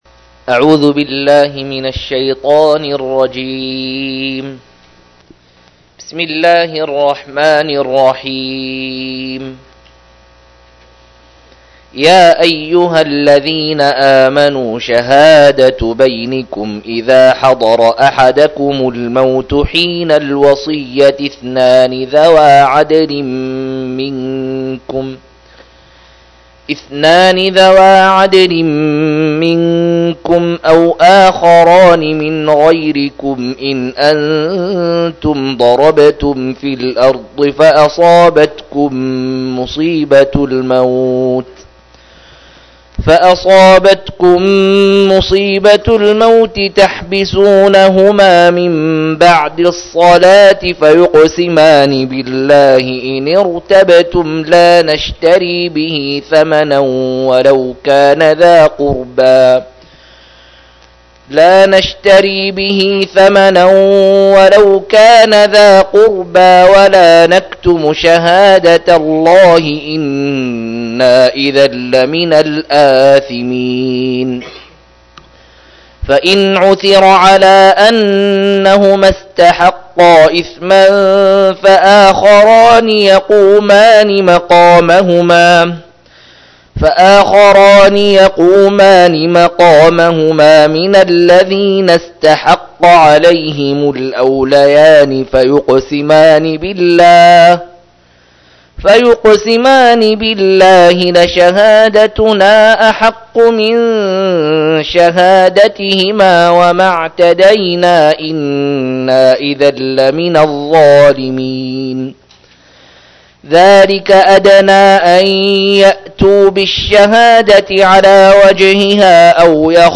123- عمدة التفسير عن الحافظ ابن كثير رحمه الله للعلامة أحمد شاكر رحمه الله – قراءة وتعليق –